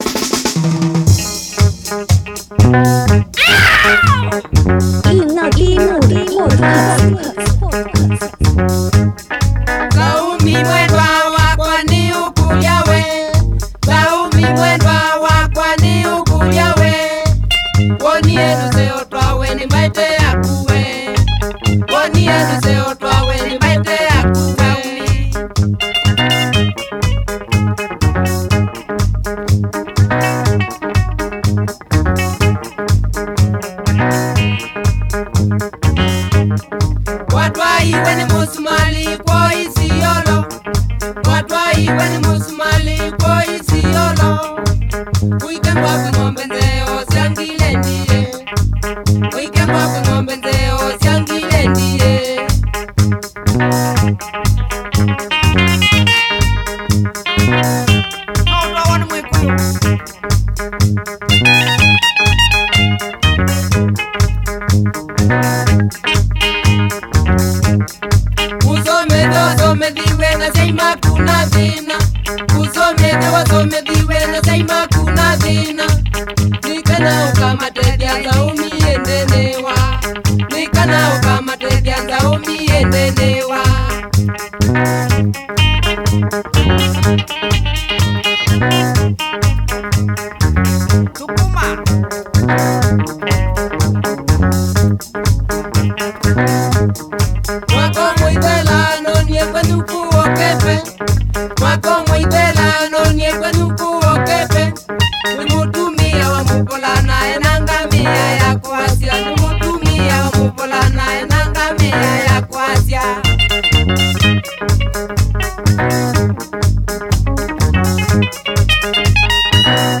1H Electro mix with a strong African taste